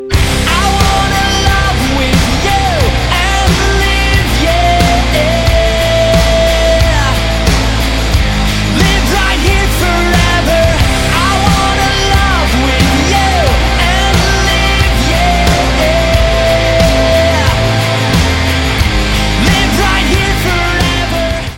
• Качество: 192, Stereo
очень похоже на поп песню 80-х годов